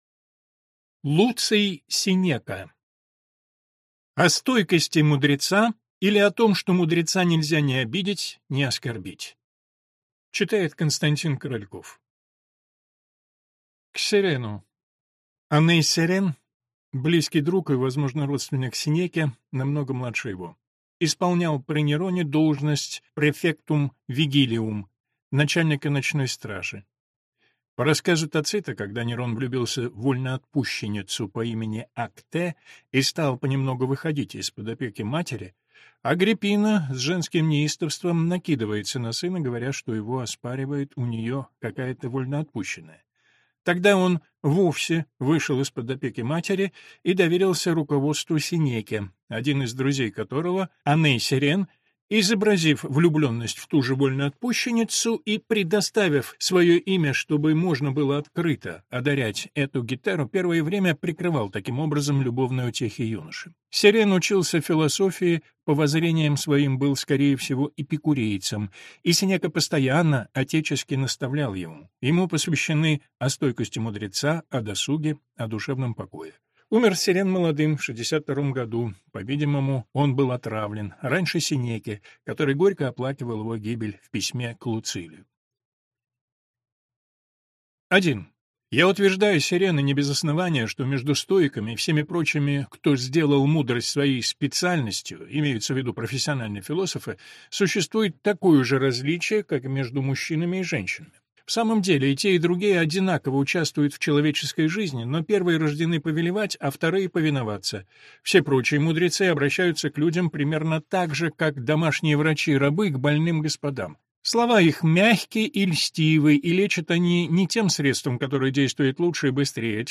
Аудиокнига О стойкости мудреца | Библиотека аудиокниг